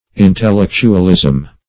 Intellectualism \In`tel*lec"tu*al*ism\, n.